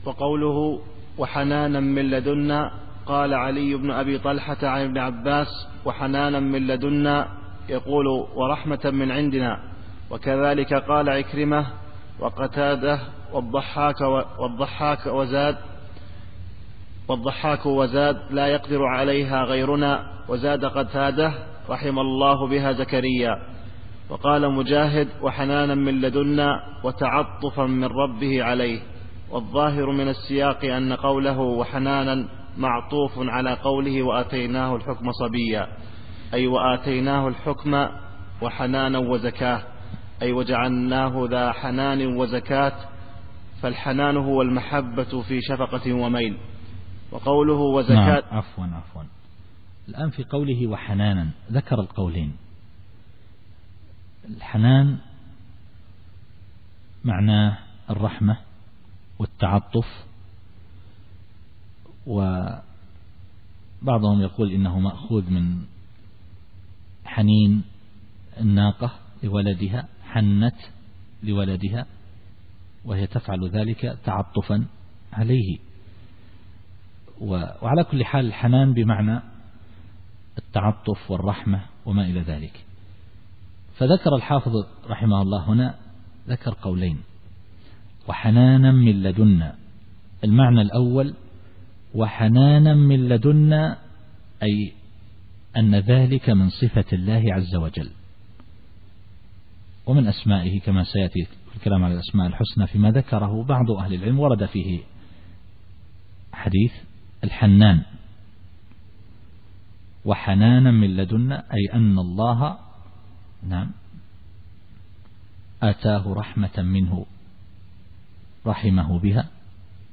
التفسير الصوتي [مريم / 13]